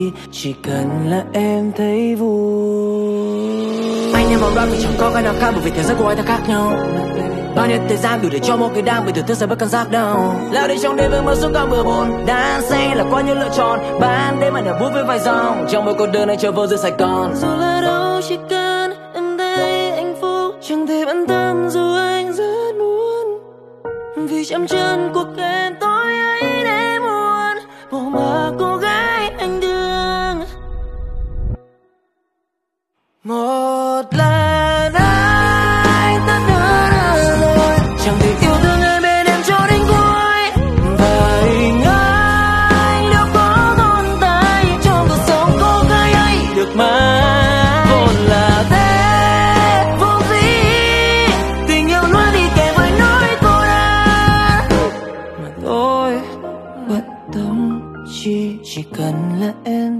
giọng hát cao vút cùng màn thả flow cực thấm